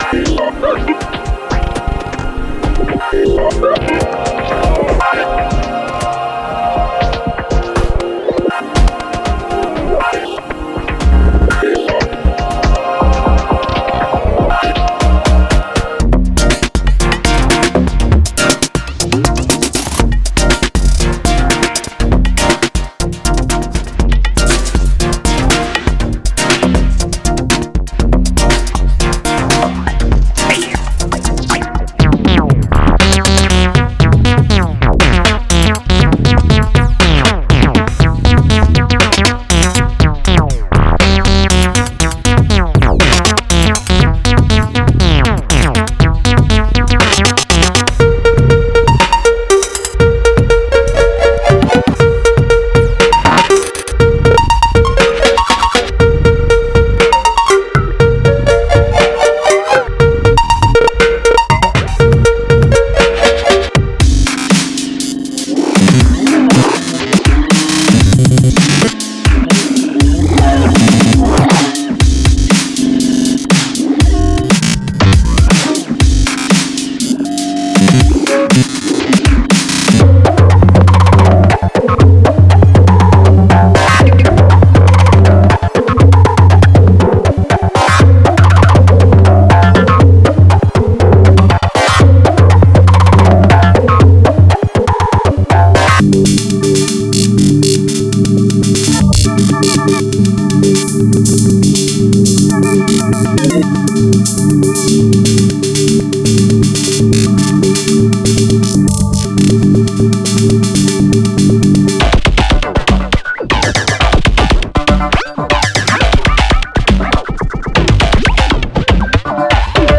Wav Loops